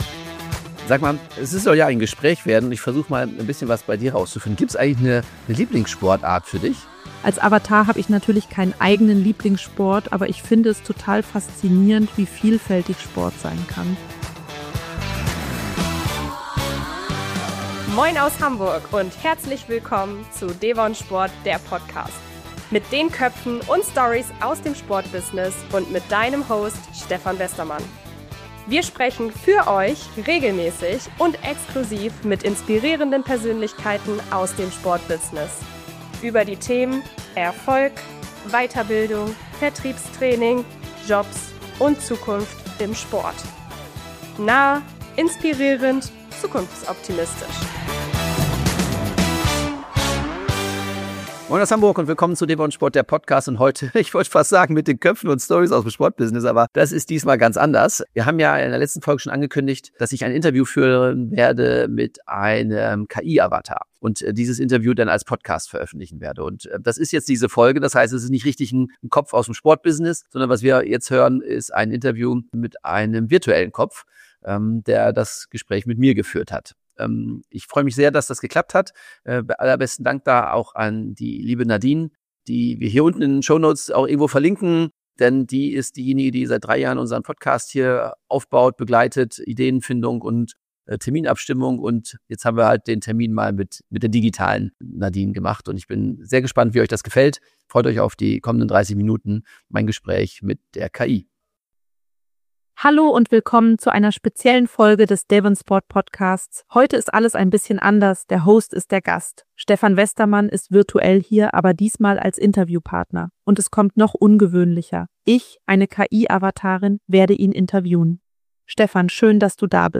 Heute wird es experimentell: Eine KI-Avatarin hat mich interviewt und das Ergebnis ist überraschend, inspirierend und stellenweise sogar emotional.
Und ja, es war an manchen Stellen holprig – aber genau das macht es authentisch.